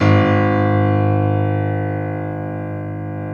Index of /90_sSampleCDs/InVision Interactive - Lightware VOL-1 - Instruments & Percussions/GRAND PIANO1